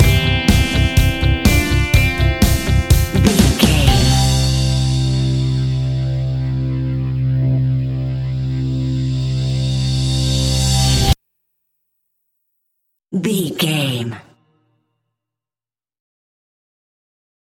Corporate Pop Rock Stinger.
Fast paced
Uplifting
Ionian/Major
fun
energetic
instrumentals
guitars
bass
drums
piano
organ